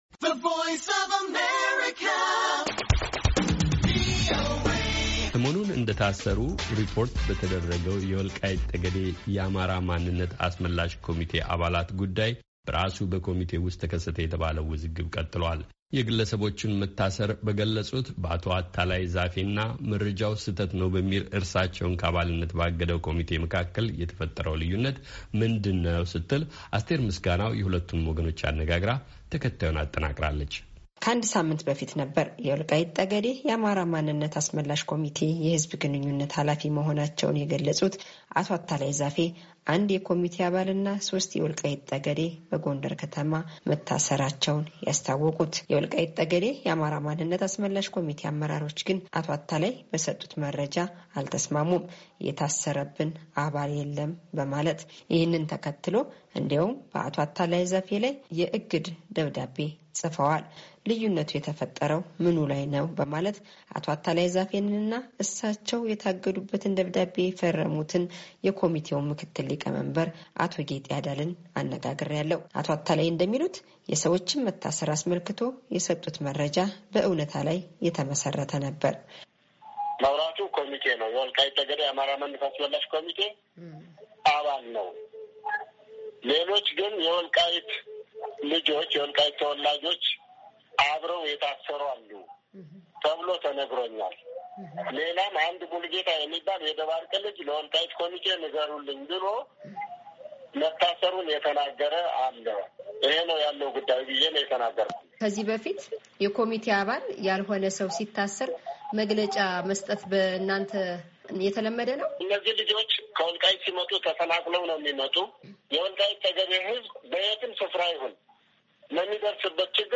ሁለቱንም ወገኖች አነጋግረናል።